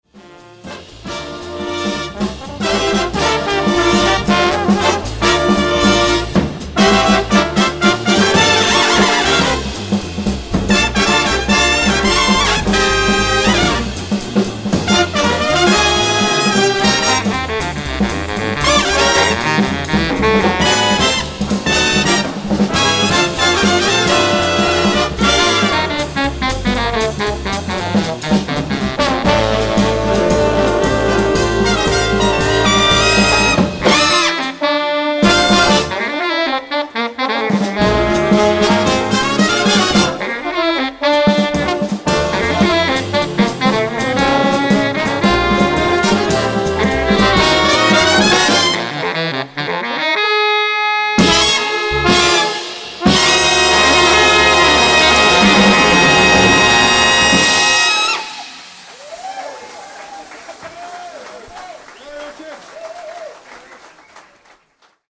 live recorded at the “Jazz Sociëteit Engels”, The Hague